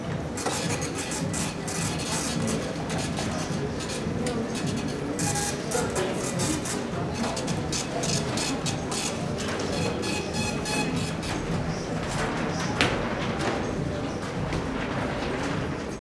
На этой странице собраны звуки магазина игрушек: весёлая суета, голоса детей, звон кассы, фоновые мелодии.
Шум покупателей в магазине игрушек